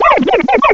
cry_not_rowlet.aif